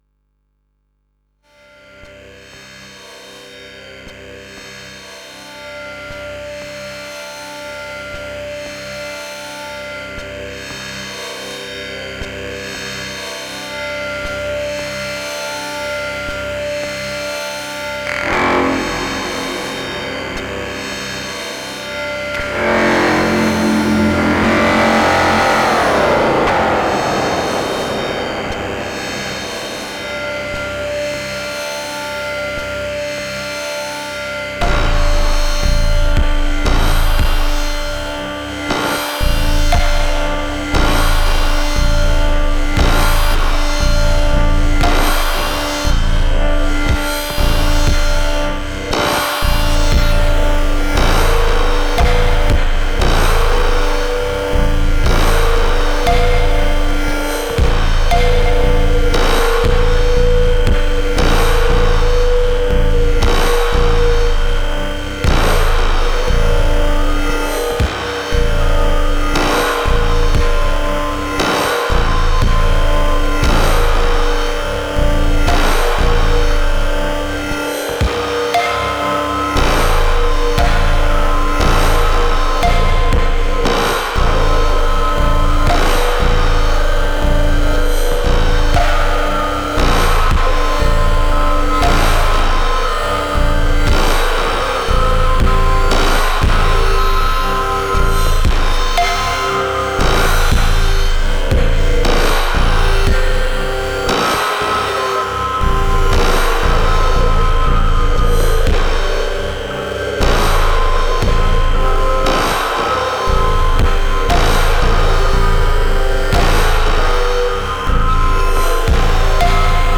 Кто занимается музыкой с использованием модульных синтезаторов?
Поэтому я записал эту славную трэшинку.